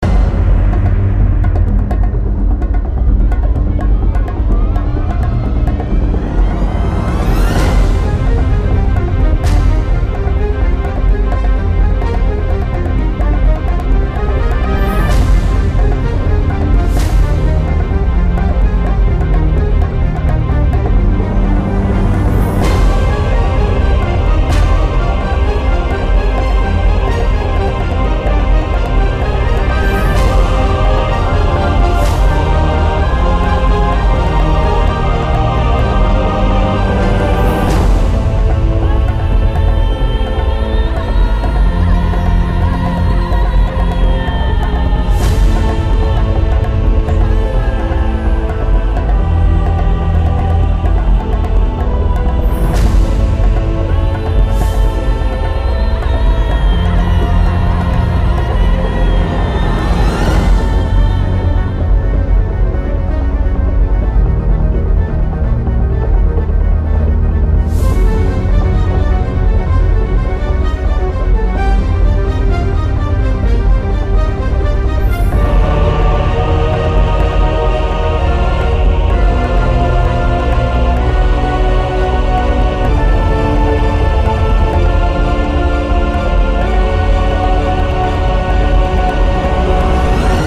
Боевая мелодия.